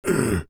Male_Grunt_Hit_21.wav